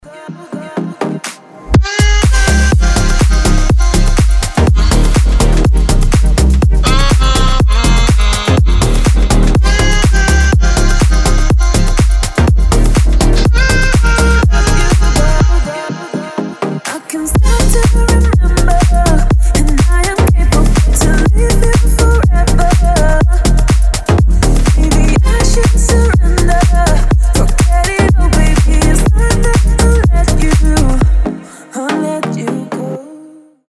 громкие
deep house